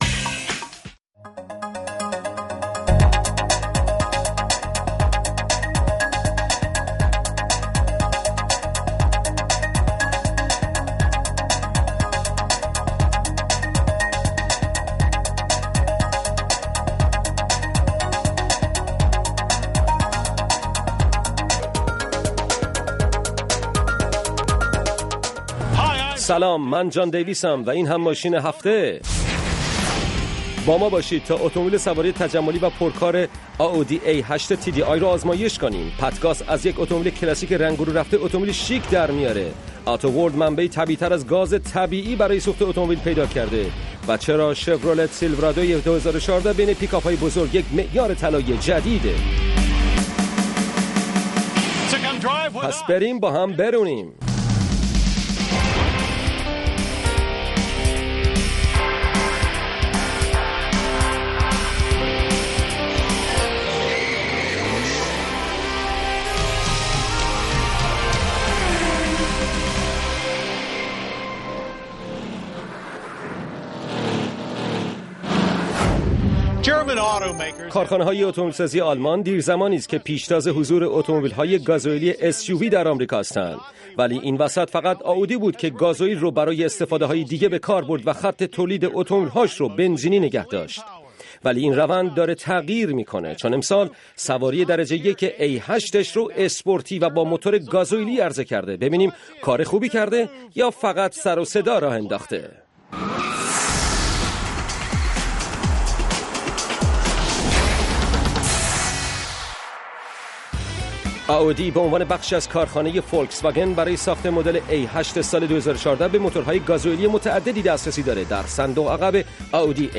روی خط برنامه ای است برای شنیدن نظرات شما. با همفکری شما هر شب یک موضوع انتخاب می کنیم و شما می توانید از طریق تلفن، اسکایپ، فیس بوک یا ایمیل، به صورت زنده در بحث ما شرکت کنید.